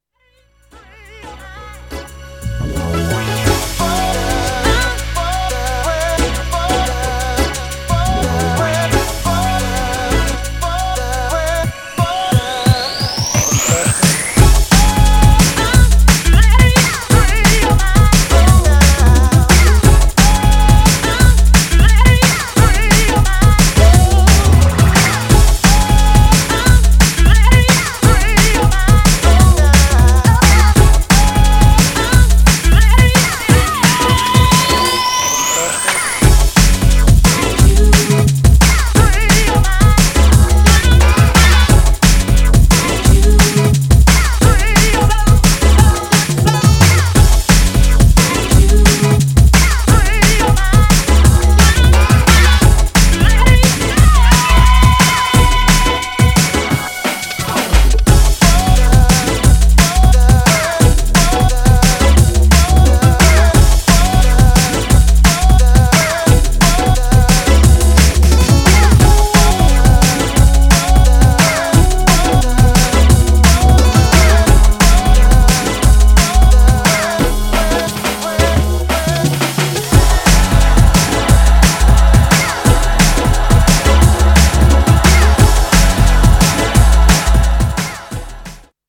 Styl: Drum'n'bass